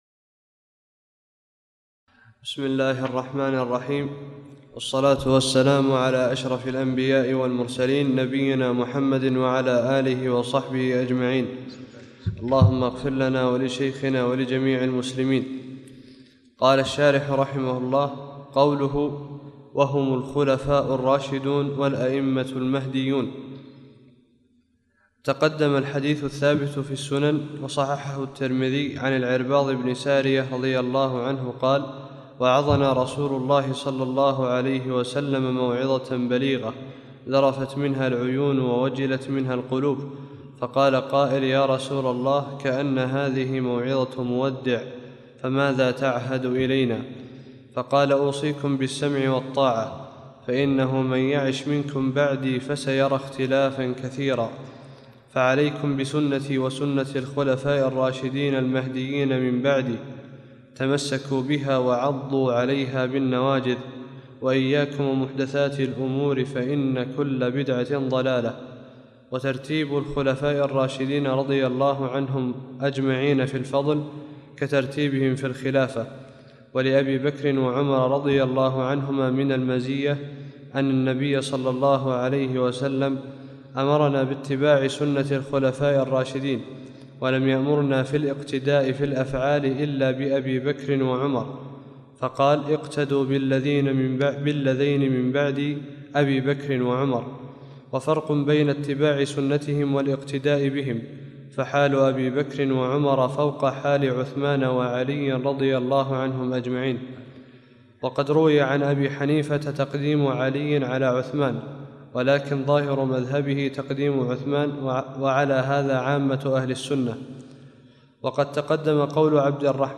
52- الدرس الثاني والخمسون الدروس التعليق على شرح الطحاوية